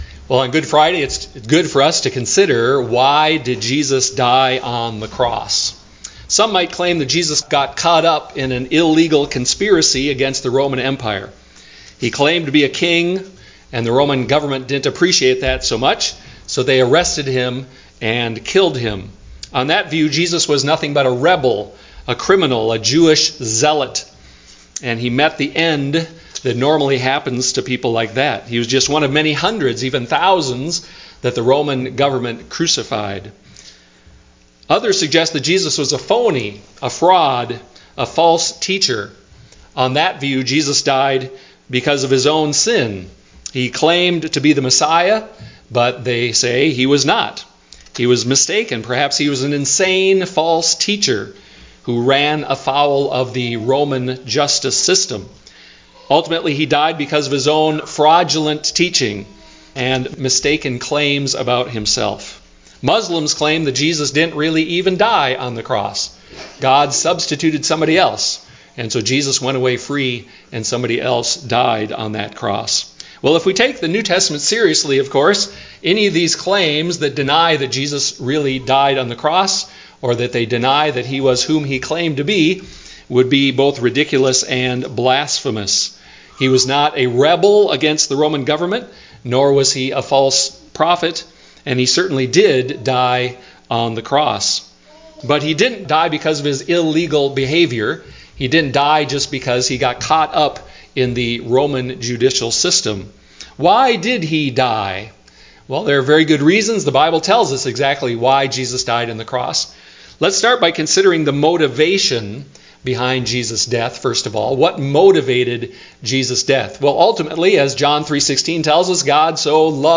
various Service Type: Special service Why did Jesus die on the cross?